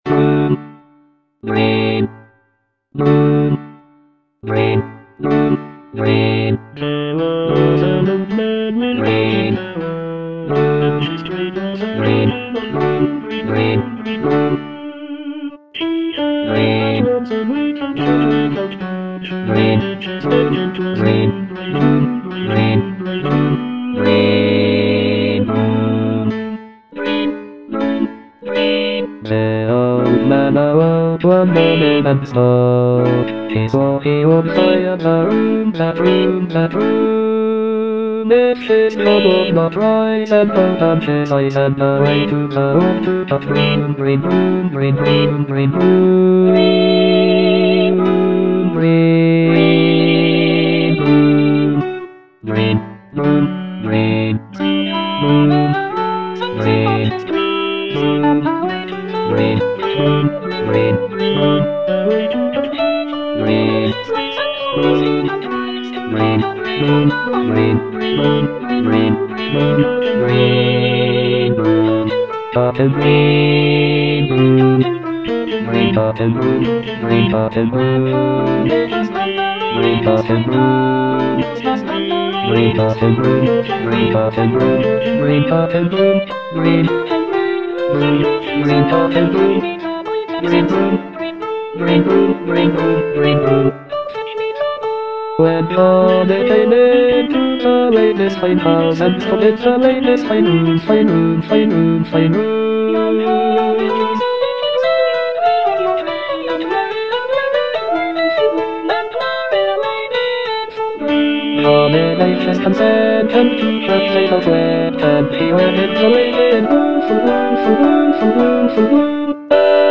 Bass II (Emphasised voice and other voices)
for voice and piano